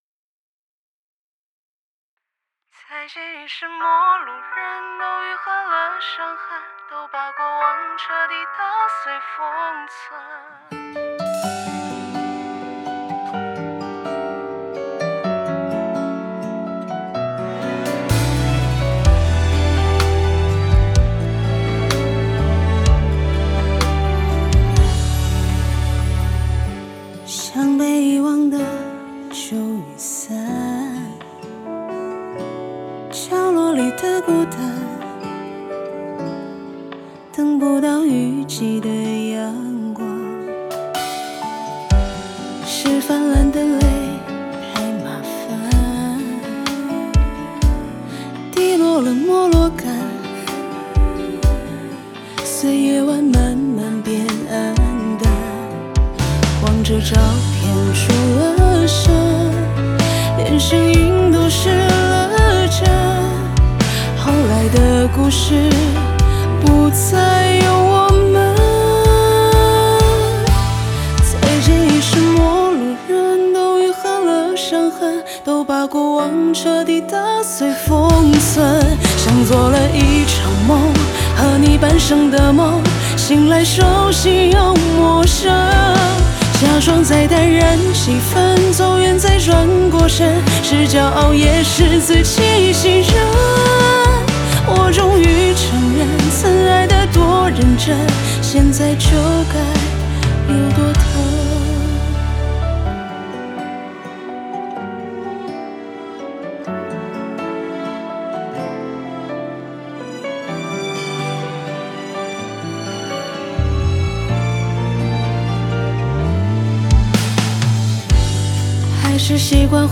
在线试听为压缩音质节选，体验无损音质请下载完整版